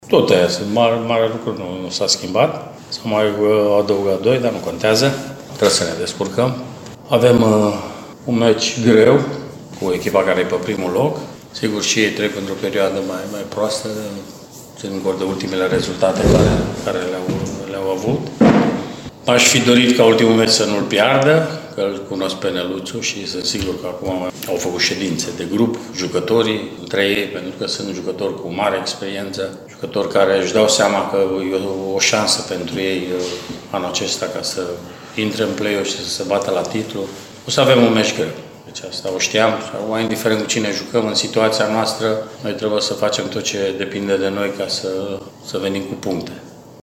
Antrenorul Mircea Rednic a comentat contextul deloc favorabil al unui joc cu liderul: